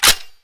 boltslap.mp3